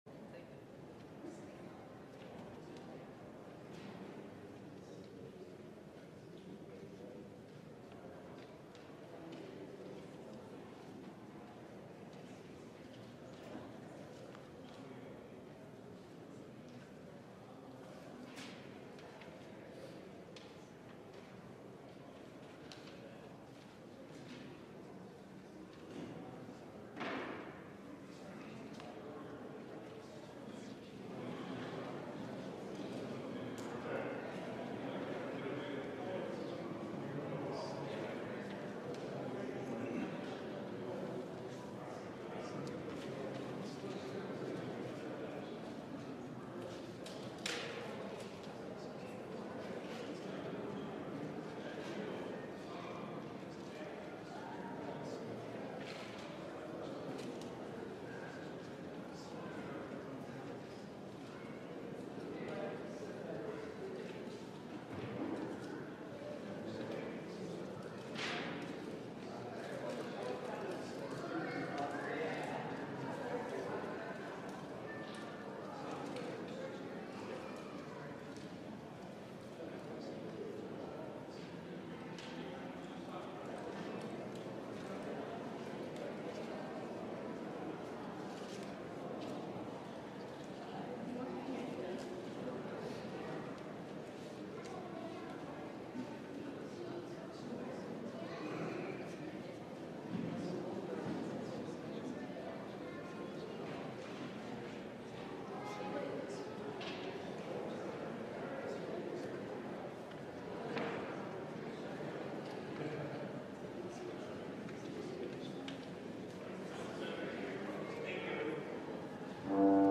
LIVE Midday Worship Service - The Image of the Invisible God: Jealousy
Congregational singing—of both traditional hymns and newer ones—is typically supported by our pipe organ.